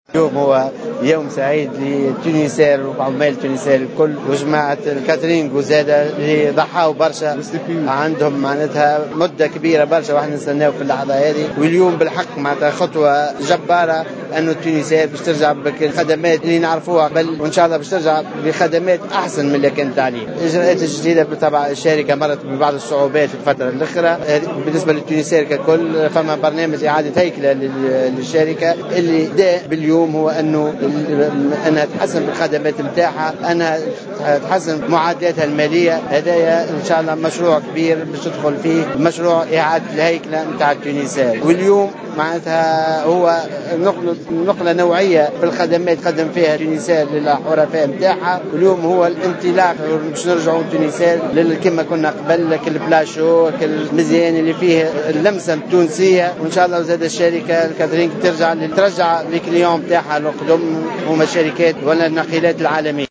وقال وزير النقل، أنيس غديرة اليوم في تصريح لمراسل "الجوهرة أف أم" إن الشركة قد تمكنت من التغلّب على الصعوبات التي مرّت بها مؤخرا، مشيرا إلى أن برنامج إعادة الهيكلة سيمكنها من تطوير قدرتها التنافسية ومزيد تحسين خدماتها المقدمة.